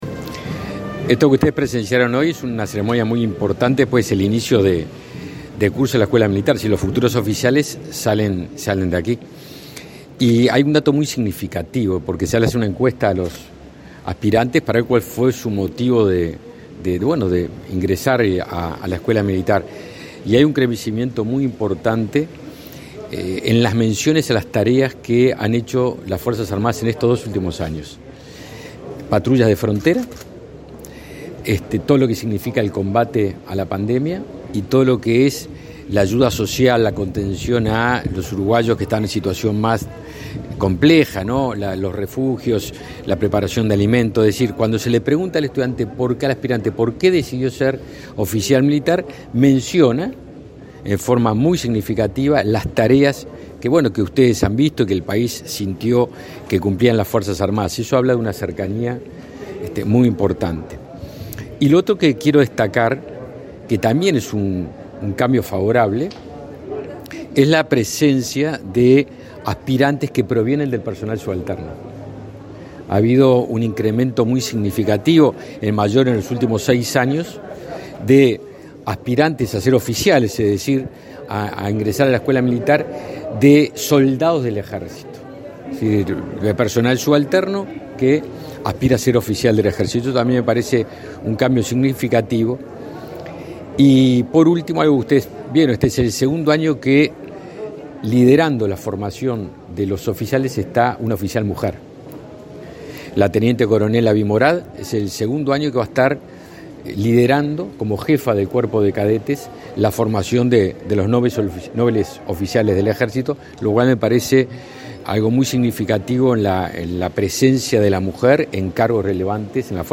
Declaraciones a la prensa del ministro de Defensa, Javier García
El ministro de Defensa Nacional, Javier García, participó este lunes 14 en el acto de inicio de cursos de la Escuela Militar, en Toledo, Canelones, y,